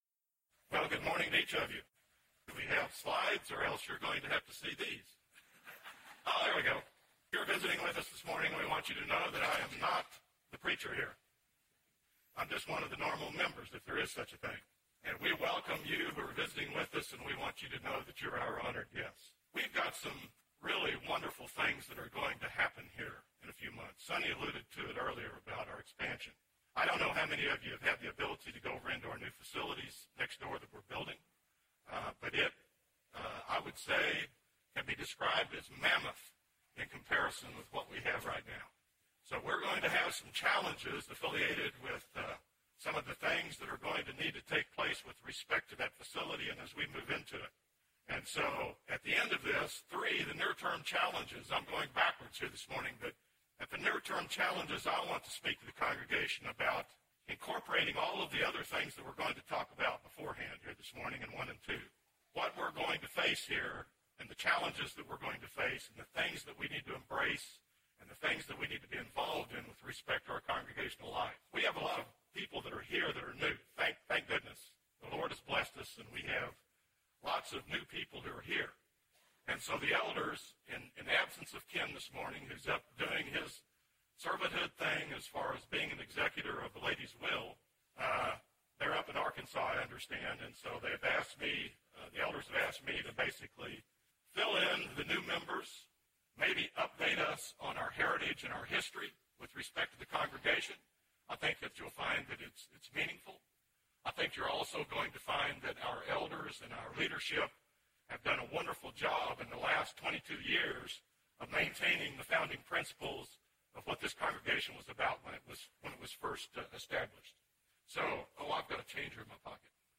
Sermon – Bible Lesson Recording